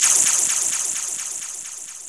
SI2 ECHO 0KR.wav